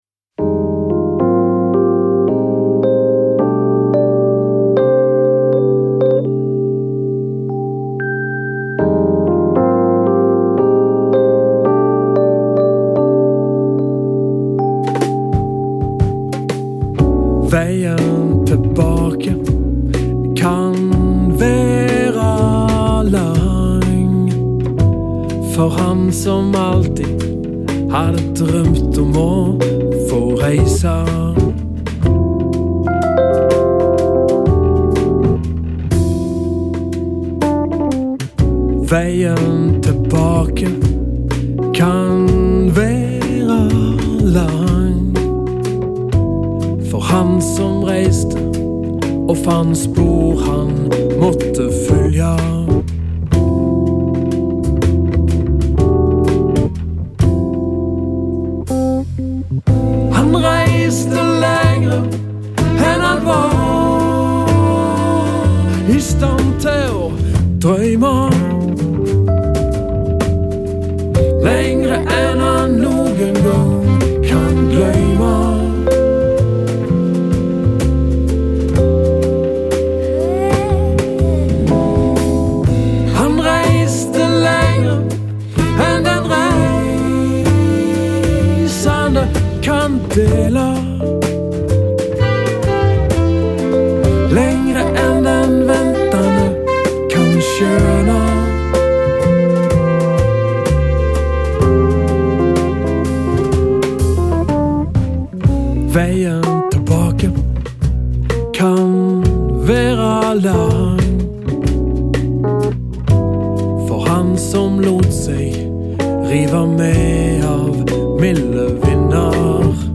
sunget med norsk tekst, på dialekt
kontrabass
trommer